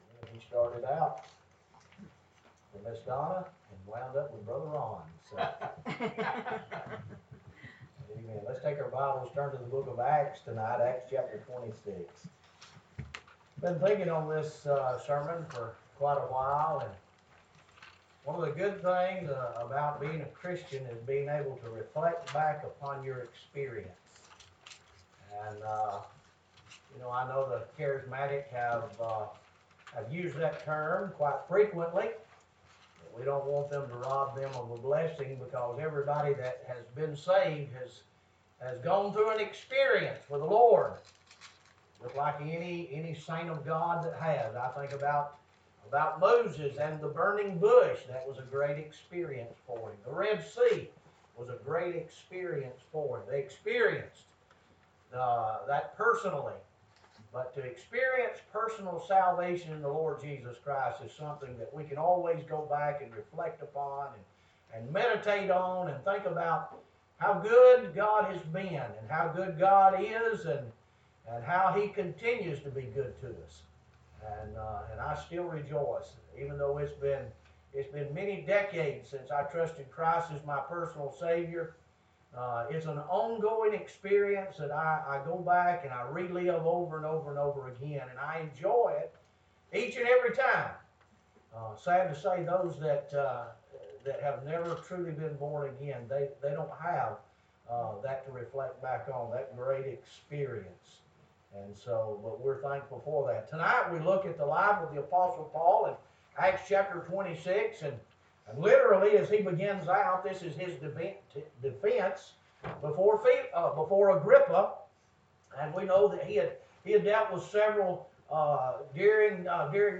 Evening Sermon
Livestream Recording